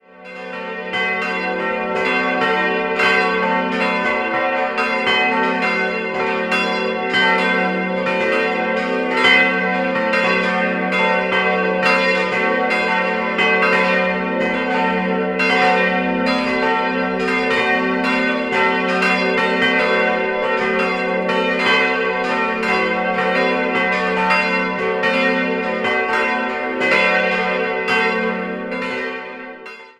Chor und Turm der Kirche stammen im Kern noch aus spätgotischer Zeit. 1858 erfolgte der Neubau des Langhauses. Idealquartett: fis'-a'-h'-d'' Die kleine Glocke wurde 1925 von der Firma Rädler in Lauingen gegossen, alle anderen stammen von Kuhn-Wolfart (Lauingen) aus dem Jahr 1950.